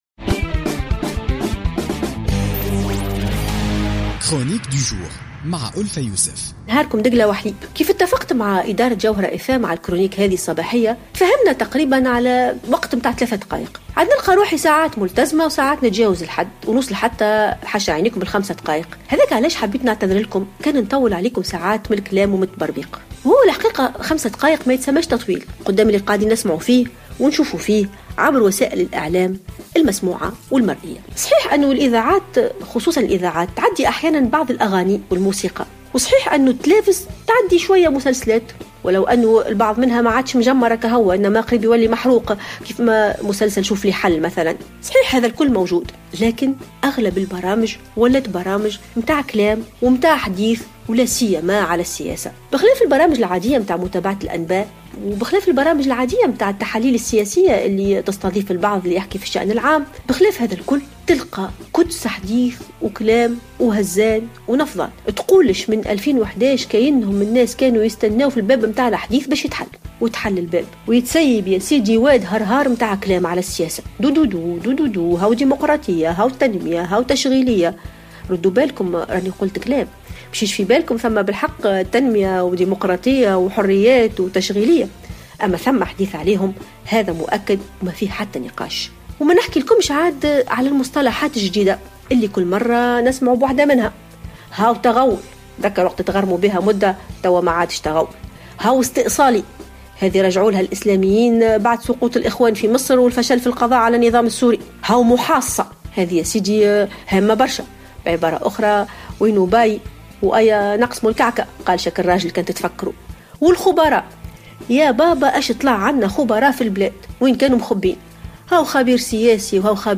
تطرقت الأستاذة الجامعية ألفة يوسف في افتتاحية اليوم الخميس 4 فيفري 2016 إلى التحاليل السياسية والنقاشات التي تطرح في أغلب البرامج في وسائل الإعلام التونسية مؤكدة أنها مجرد نقاشات عقيمة لا فائدة منها .